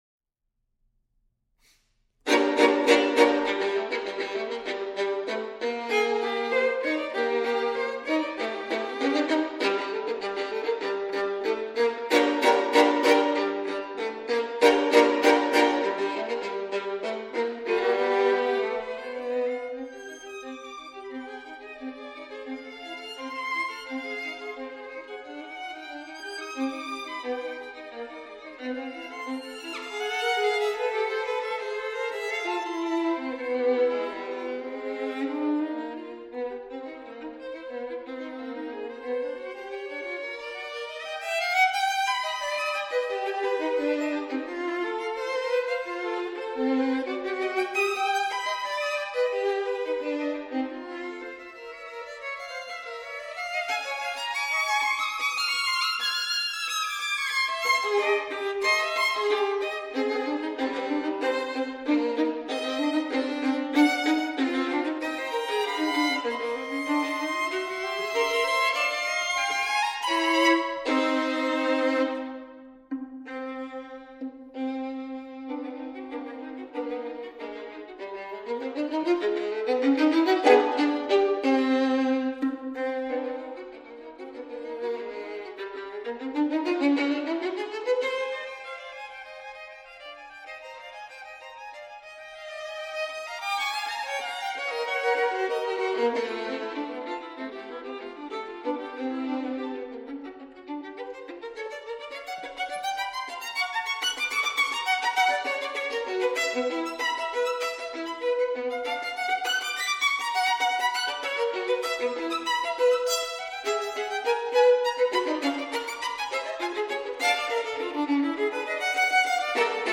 DUO VIVO
Sonata for 2 violins, Op. 56
II. Allegro
sergei-prokofiev_sonate-pour-2-violon-opus-56_ii.-allegro.mp3